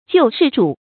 救世主 注音： ㄐㄧㄨˋ ㄕㄧˋ ㄓㄨˇ 讀音讀法： 意思解釋： 基督徒對耶酥的稱呼。